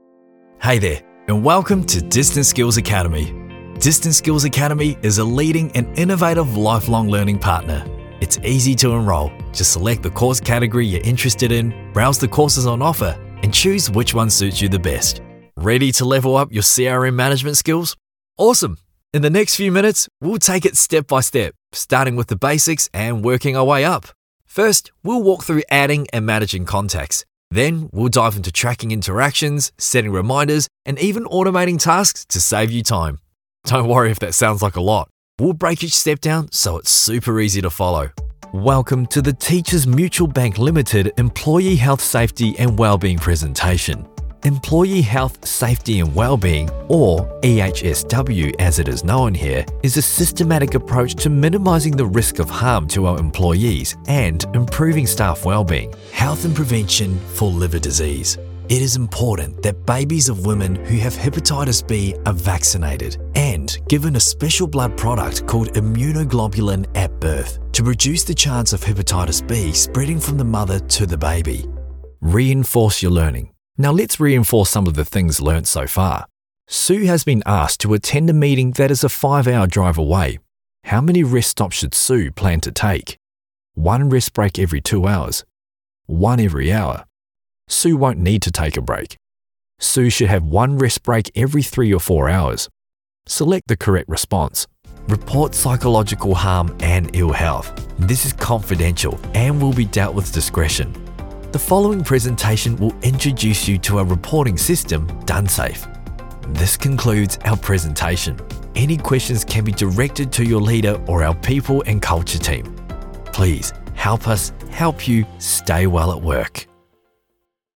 Male
Adult (30-50), Older Sound (50+)
Professional Australian and New Zealand male voice artist and actor with over 10 years performance experience on stage, film and opera, tailoring authenticity to your message.
E-Learning Demo
All our voice actors have professional broadcast quality recording studios.